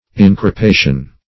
Search Result for " increpation" : The Collaborative International Dictionary of English v.0.48: Increpation \In`cre*pa"tion\, n. [L. increpatio.] A chiding; rebuke; reproof.